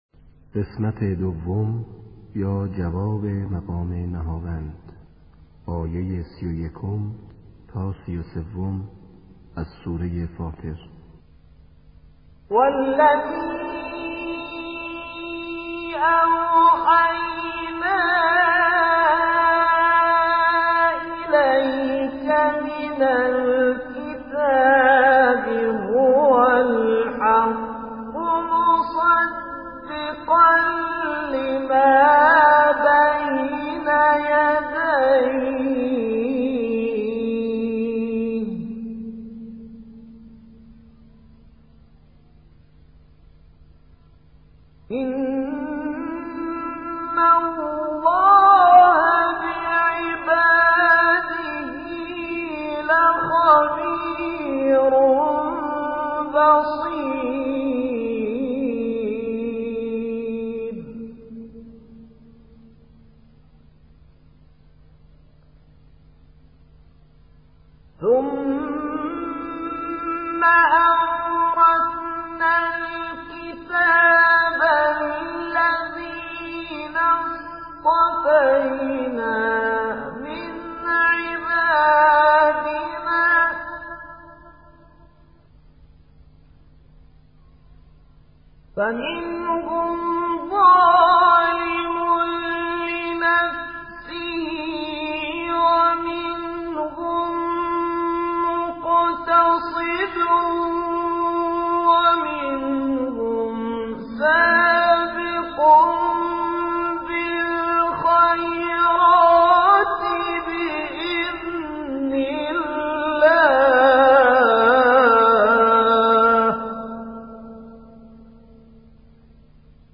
جواب مقام نهاوند.mp3
• مقام, جواب نهاوند, مقام نهاوند, جواب نغمه نهاوند, نغمه, قرائت قرآن, نهاوند, جواب مقام نهاوند
جواب-مقام-نهاوند.mp3